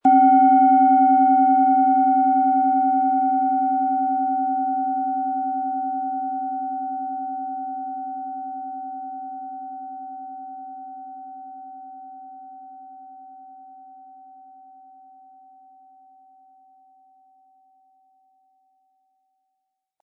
OM Ton
Wie klingt diese tibetische Klangschale mit dem Planetenton OM-Ton?
Im Sound-Player - Jetzt reinhören können Sie den Original-Ton genau dieser Schale anhören.